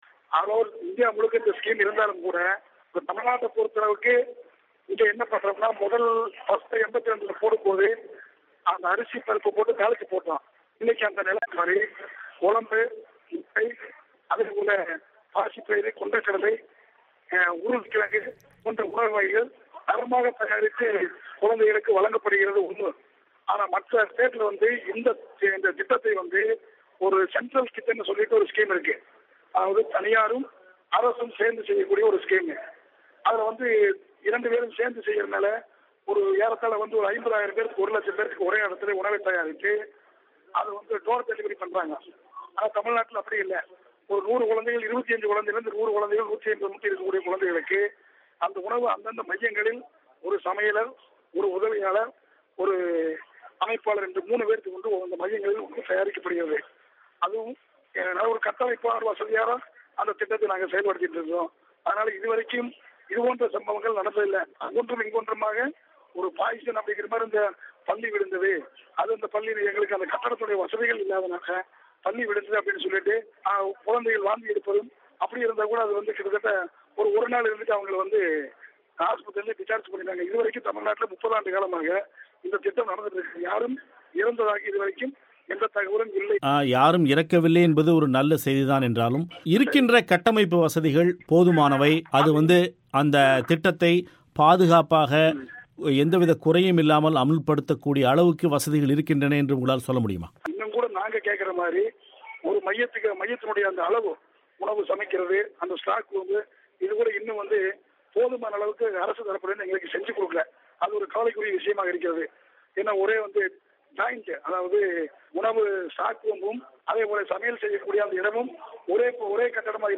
ஒரு பேட்டி.